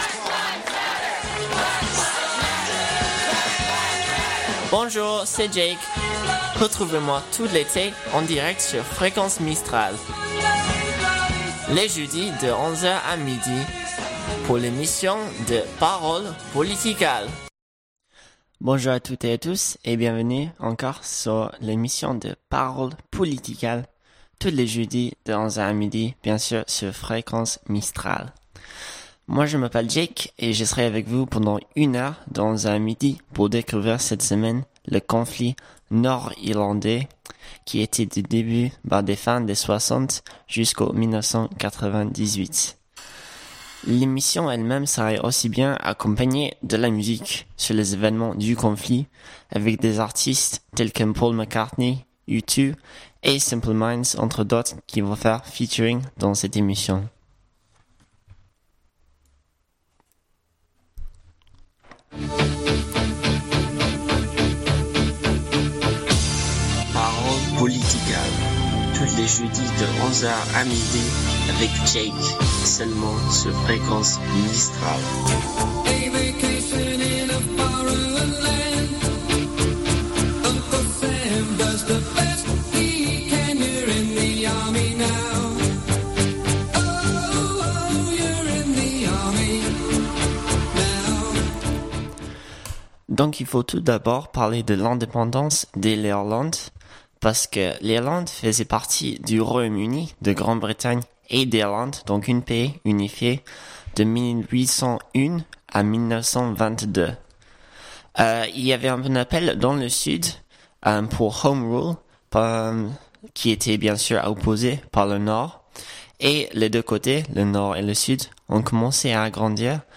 Une émission musicale-politique!